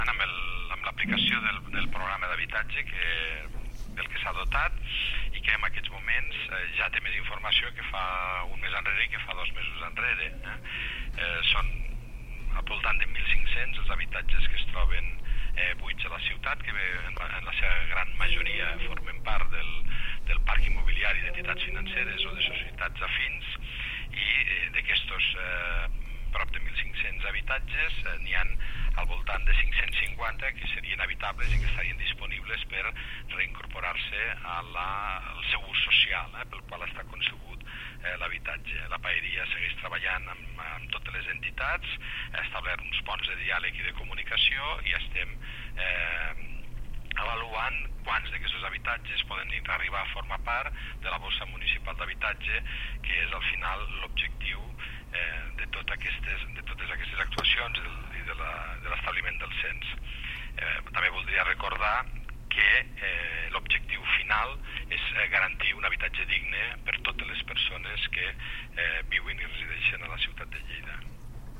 Inclou tall de veu de Fèlix Larrosa.
tall-de-veu-de-felix-larrosa-sobre-el-cens-dhabitatges-buits